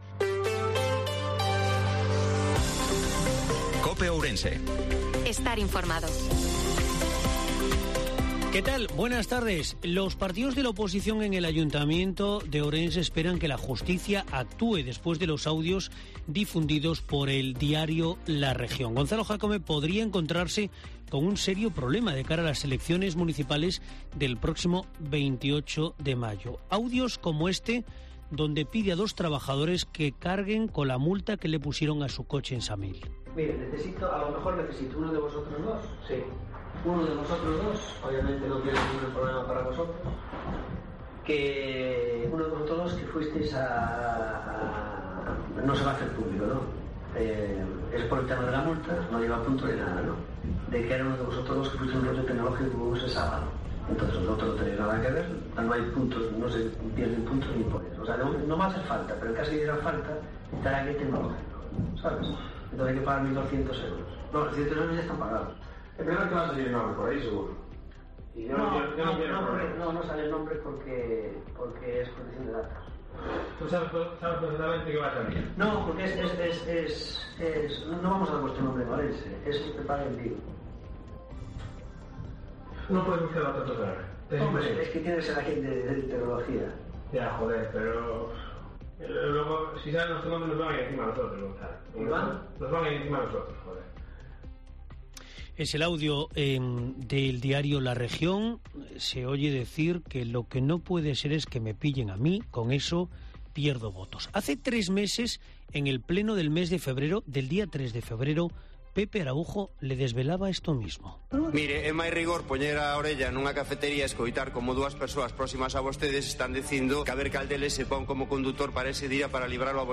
INFORMATIVO MEDIODIA COPE OURENSE-08/05/2023